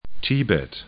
'ti:bɛt